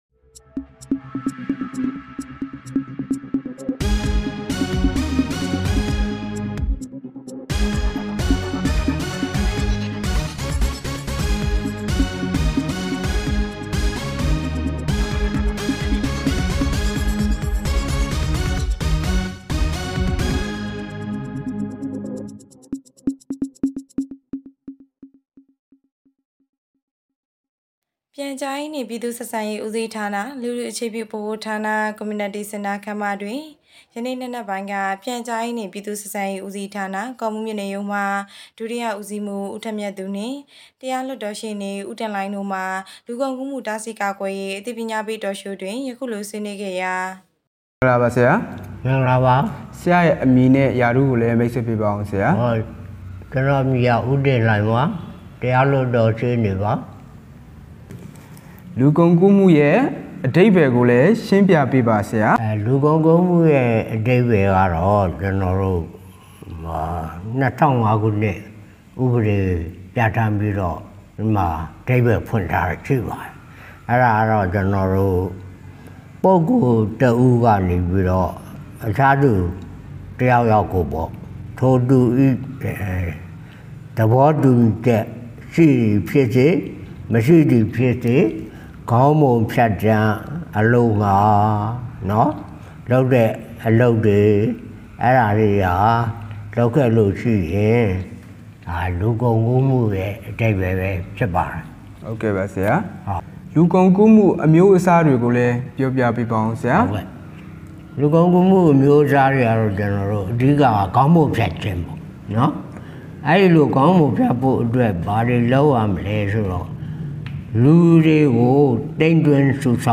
ကော့မှူးမြို့၌ လူကုန်ကူးမှုတားဆီးကာကွယ်ရေးအသိပညာပေး Talk Show